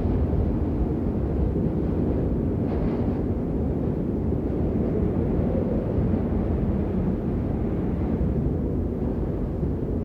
blizzard.ogg